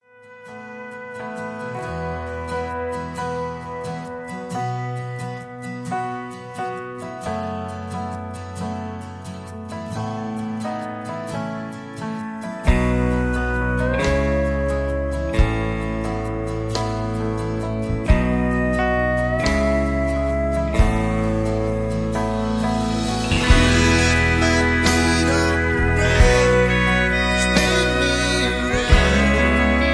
(Key-E)